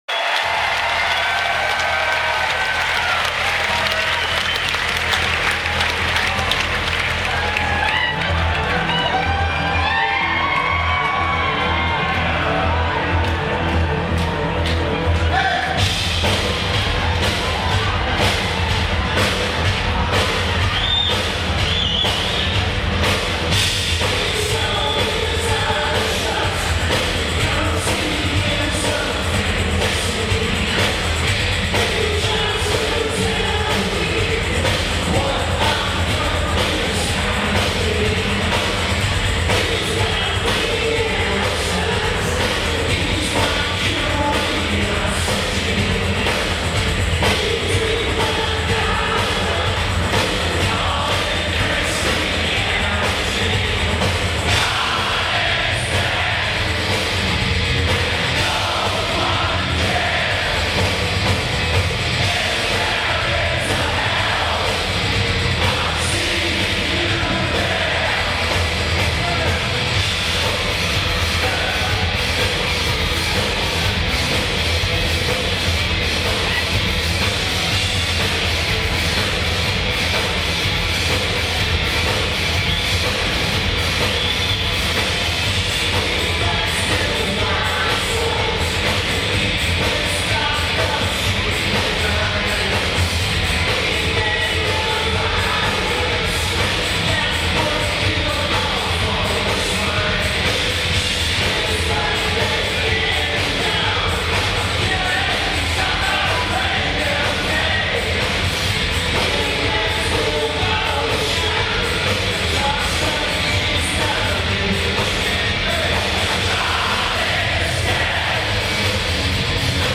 O2 Academy Glasgow
Lineage: Audio - AUD (Sony ICD-UX71)